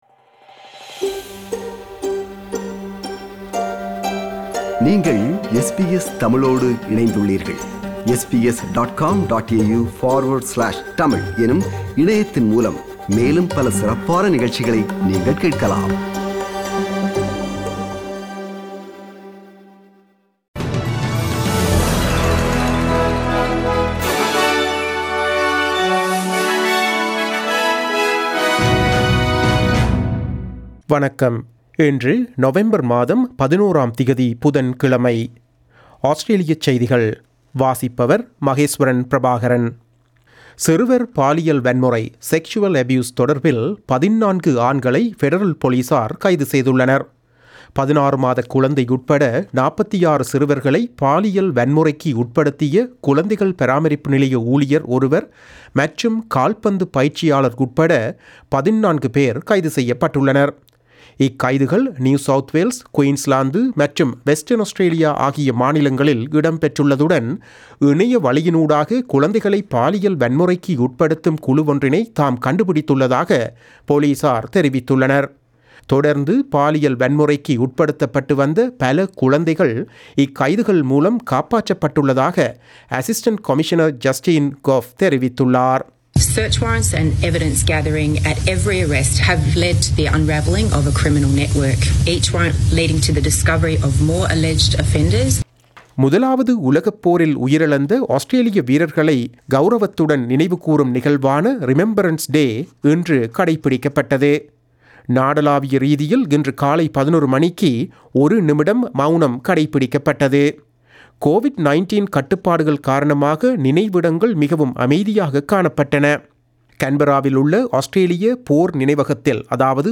Australian news bulletin for Tuesday 11 November 2020.